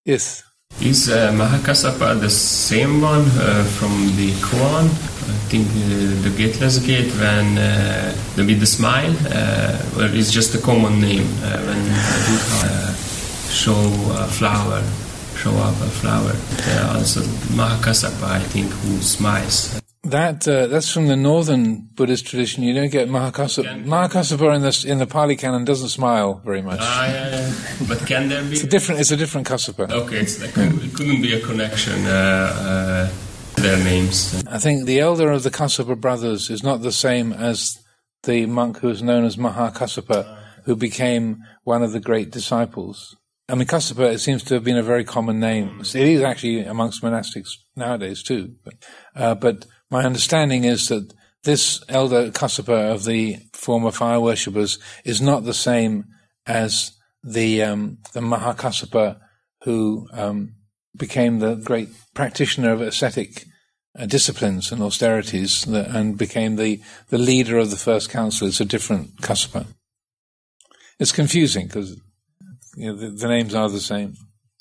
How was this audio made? Readings from The Island, Session 6 – Jan. 16, 2025